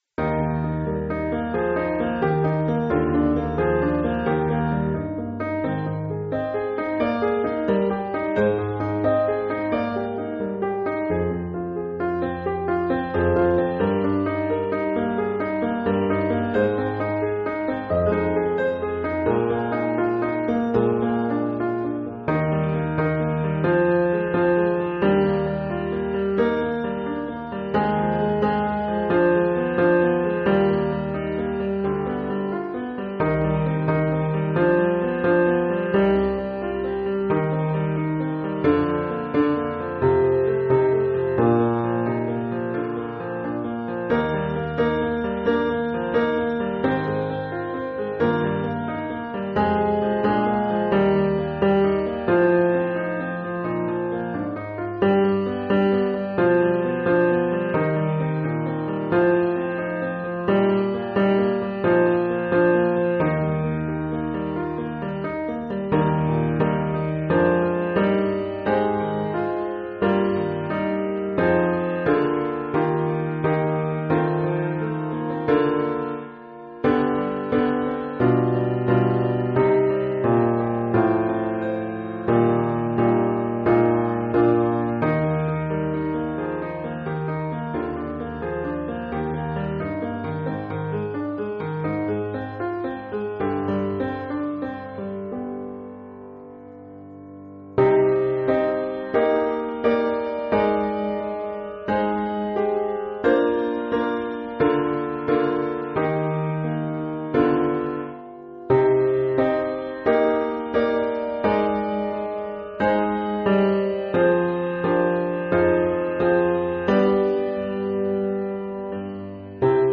Like a River Glorious – Bass